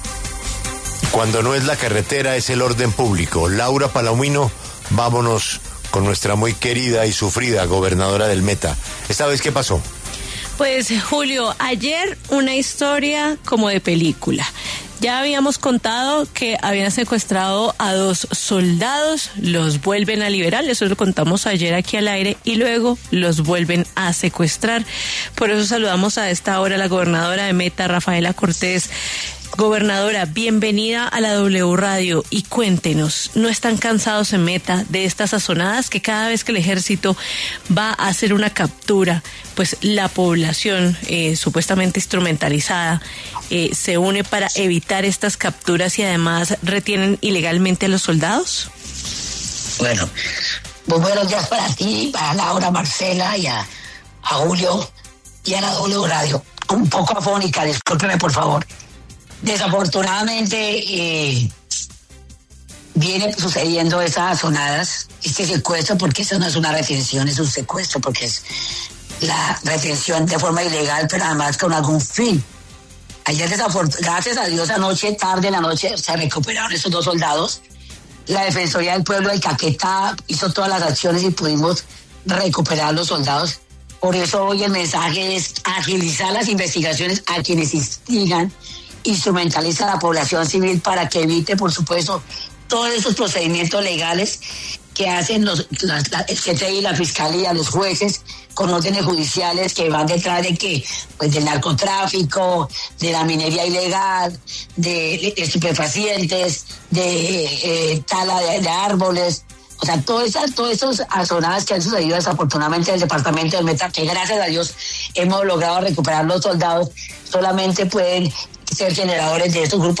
La gobernadora de Meta, Rafaela Cortes, habló en W Radio sobre el doble secuestro de dos soldados en el departamento.